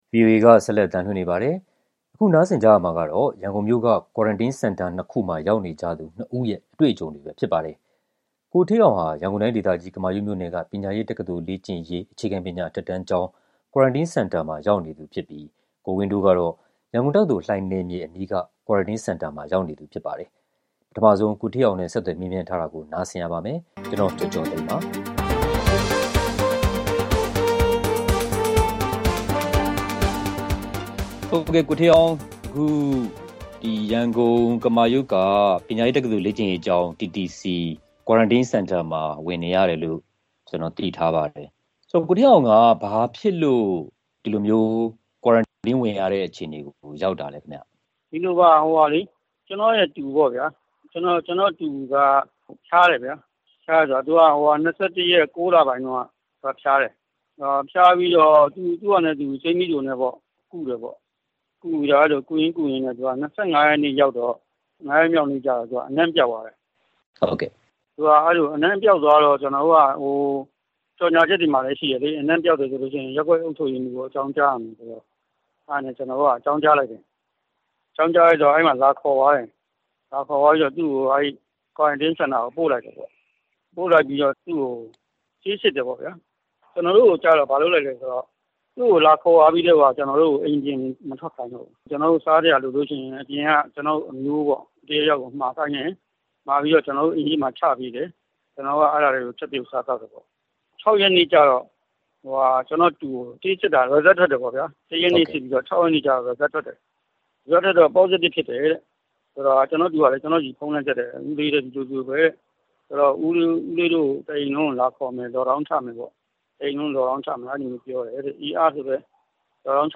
COVID-19 ကူးစက်လူနာနဲ့ တိုက်ရိုက်ထိတွေ့မှုရှိလို့ Quarantine ဝင်ရသူနဲ့ ကိုယ်တိုင် ပိုးကူးစက်လို့ Quarantine ဝင်ရသူတို့ရဲ့ အတွေ့အကြုံတွေကို ဆက်သွယ်မေးမြန်းထားပါတယ်။
ရန်ကုန်မြို့မှာ Quarantine ဝင်ကြရသူနှစ်ဦးရဲ့ အတွေ့အကြုံ (အင်္ဂါည ဆွေးနွေးခန်း)